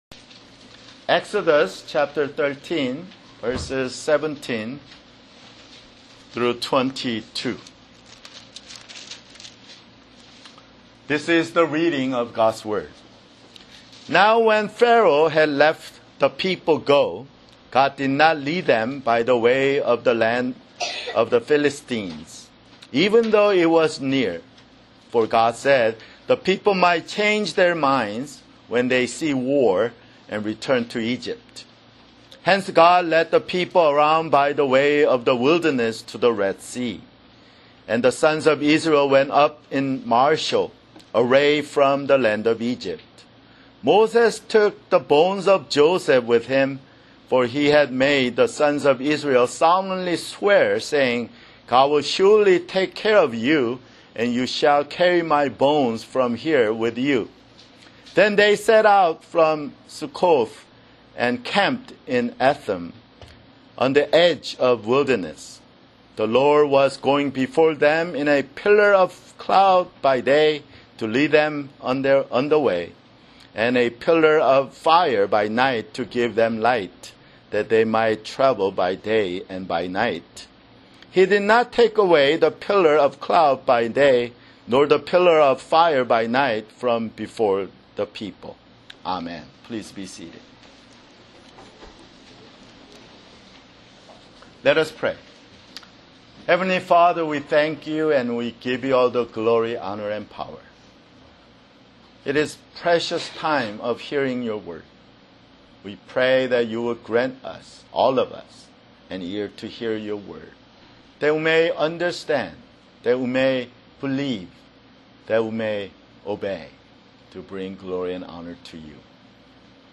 [Sermon] Exodus (31)